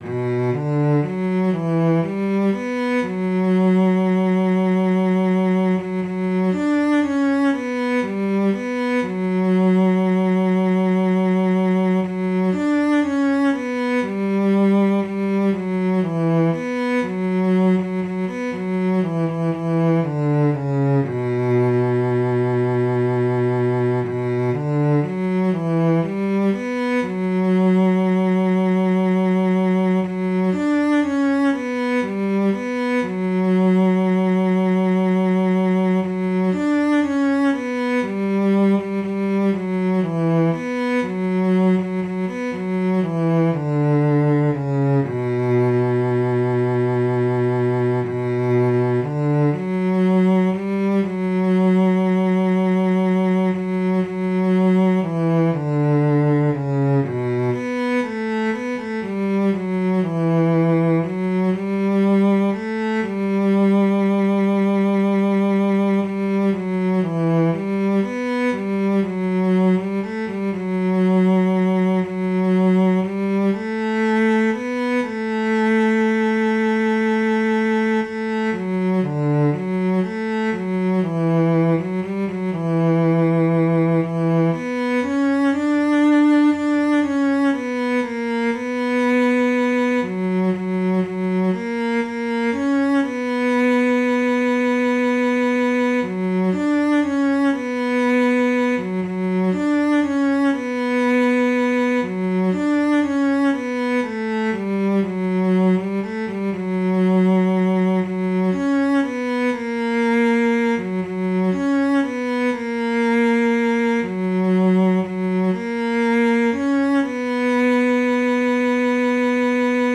Jewish Folk Song - popular by Seudah Shlishit (Aish HaTorah setting)
B minor ♩= 120 bpm